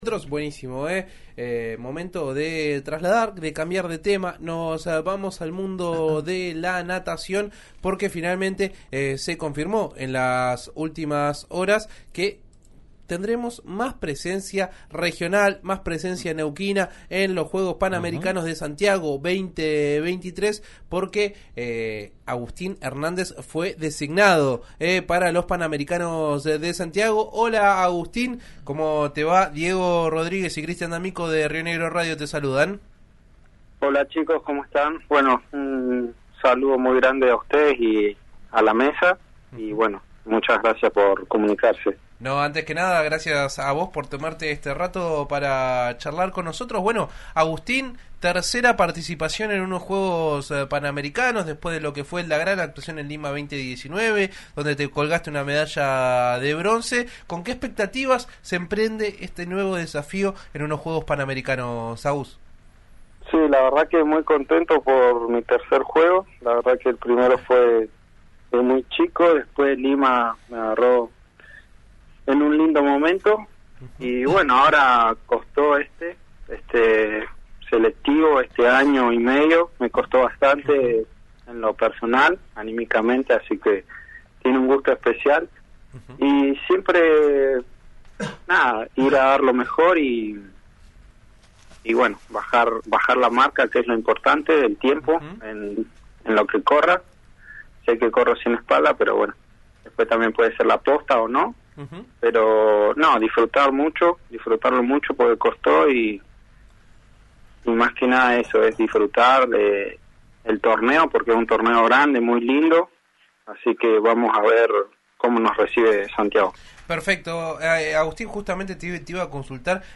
“Estoy muy contento por mi tercera participación en los Juegos. Toronto 2015 me agarró muy joven, en Perú estaba un lindo momento y este último año y medio me costó mucho en lo personal, asi que tiene un gusto especial para mi”, le comentó a ‘Entre Redes’ de Río Negro Radio.